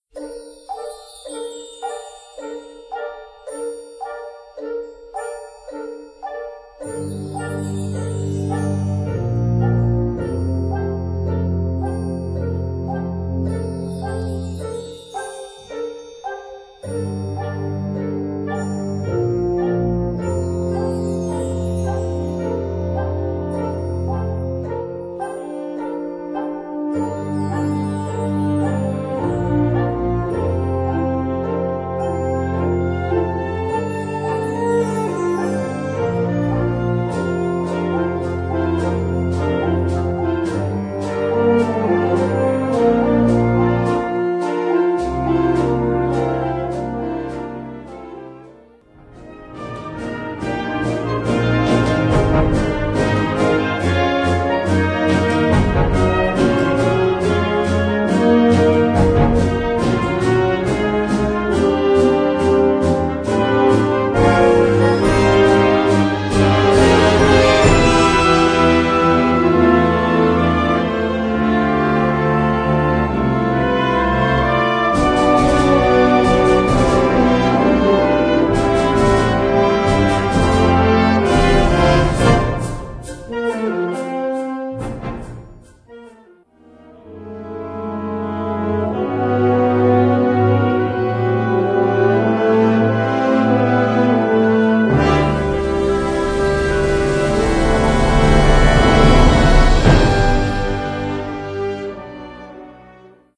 Categorie Harmonie/Fanfare/Brass-orkest
Subcategorie Suite
Bezetting Ha (harmonieorkest)